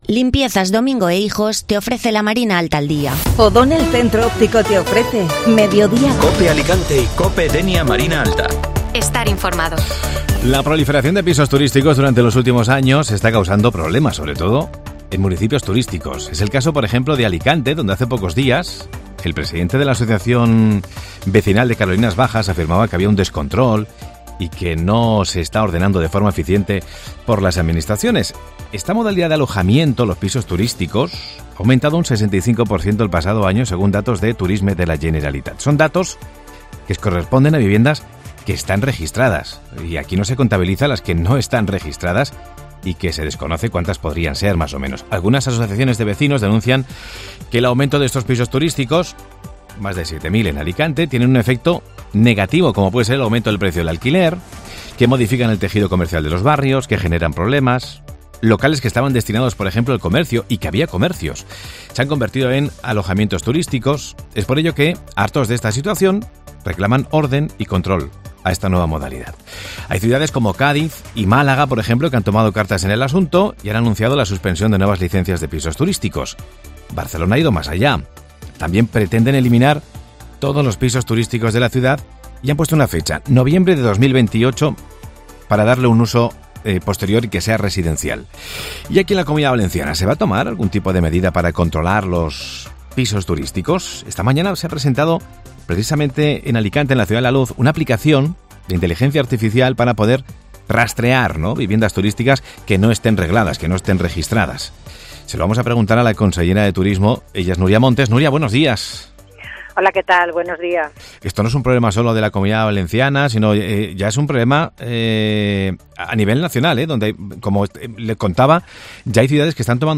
Una App con inteligencia artificial rastreará viviendas turísticas que no estén registradas en la Comunidad Valenciana. Escucha la entrevista a Nuria Montes, Consellera de Turismo